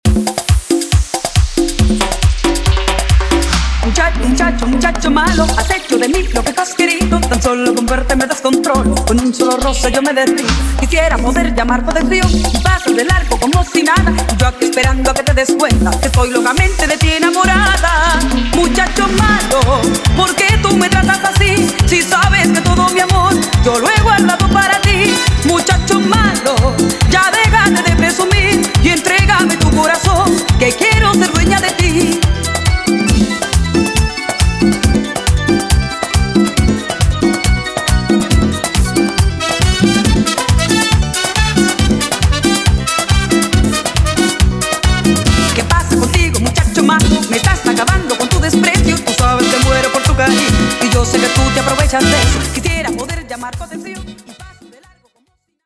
guaracha, salsa remix, cumbia remix, EDM latino